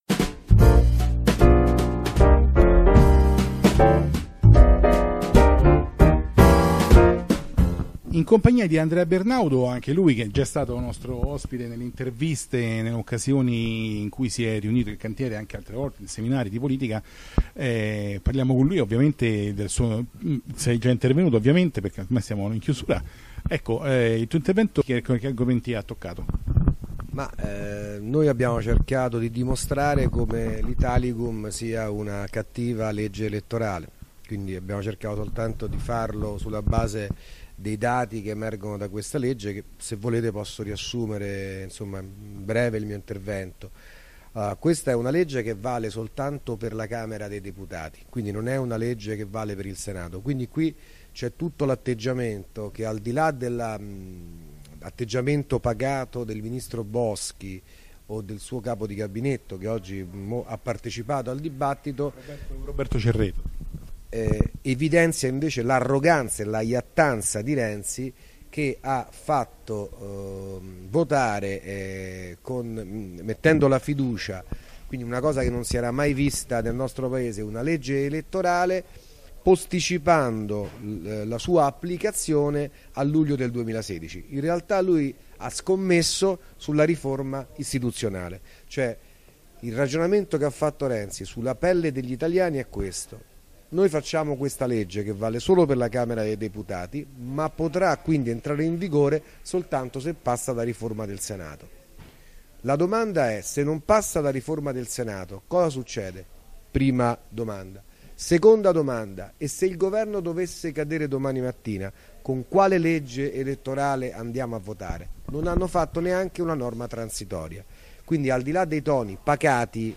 Seminario "RIFORME ISTITUZIONALI: Problemi e prospettive" del 15 maggio 2015 presso il River Chateau Hotel di Roma, organizzato dall'associazione di cultura politica IL CANTIERE.
Intervista